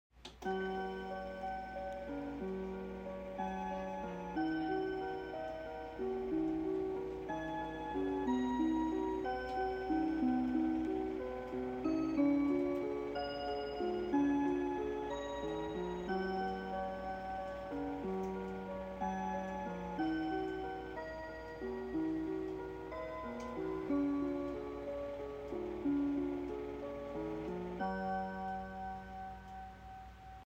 sounds so good on this clock